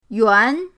yuan2.mp3